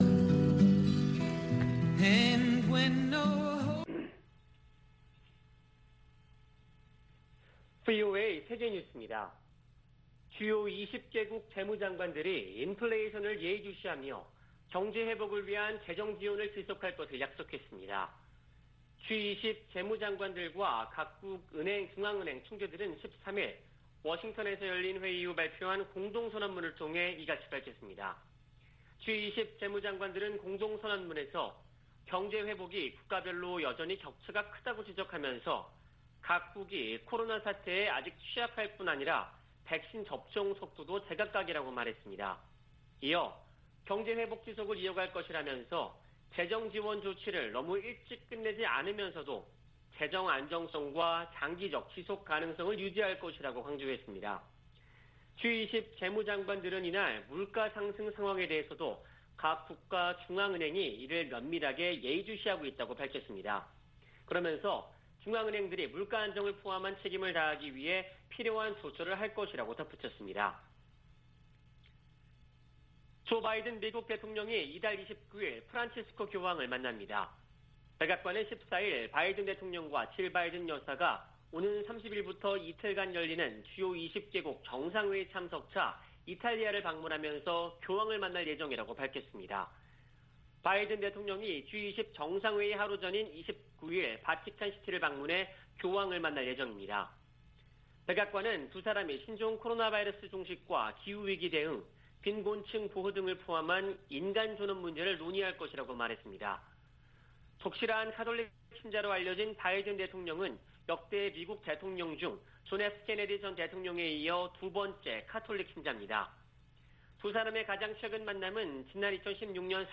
VOA 한국어 아침 뉴스 프로그램 '워싱턴 뉴스 광장' 2021년 10월 15일 방송입니다. 미 국가정보국장이 북한을 전통적인 위협으로 꼽았습니다. 미 공화당 의원들이 내년 11월 중간선거를 앞두고 조 바이든 대통령의 대외 정책을 비판하며 공세를 강화하고 있습니다. 한국 정부가 종전선언 문제를 거듭 제기하면서 미한 동맹의 북한 논의에서 주요 의제로 떠오르고 있습니다.